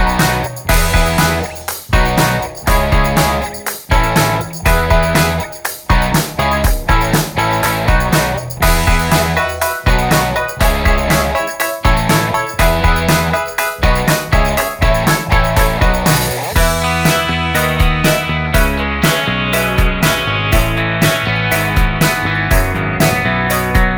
No Lead Guitar Rock 3:28 Buy £1.50